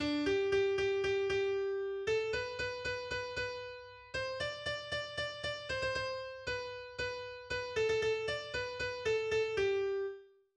Volkslkied des 18. Jahrhunderts